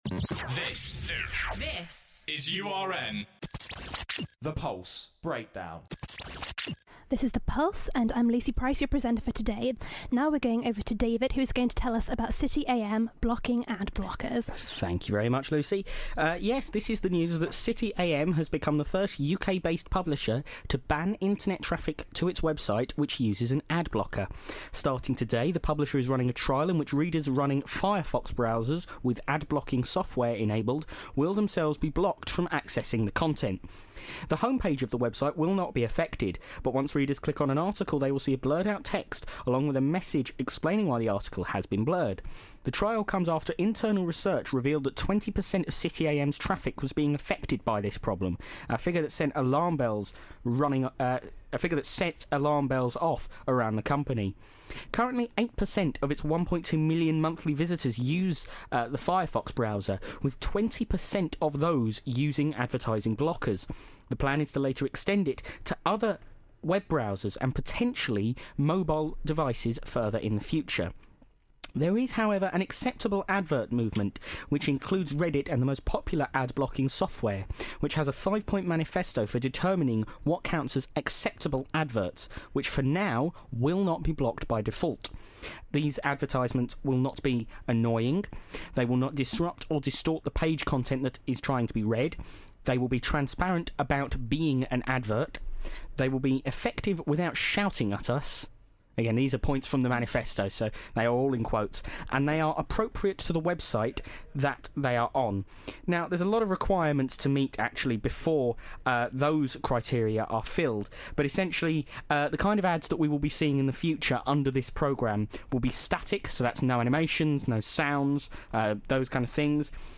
Join The Pulse every weekday from 5 - 6pm on URN discussing the news that matters to you.